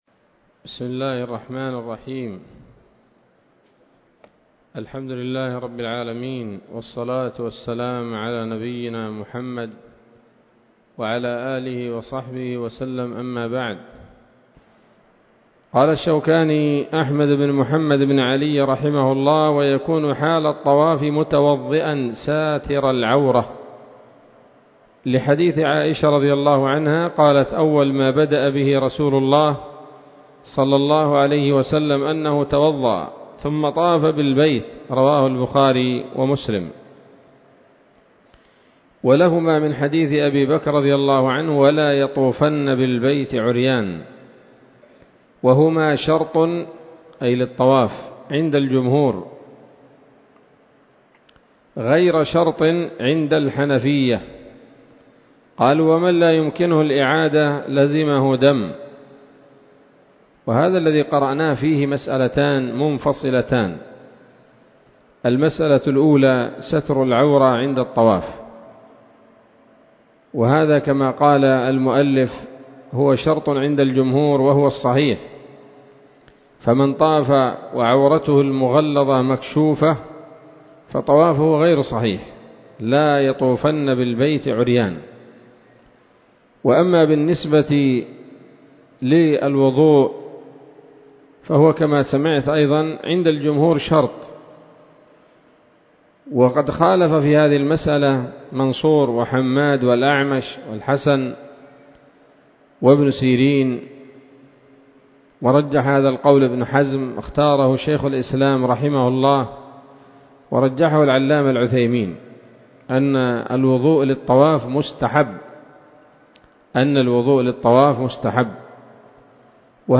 الدرس الرابع عشر من كتاب الحج من السموط الذهبية الحاوية للدرر البهية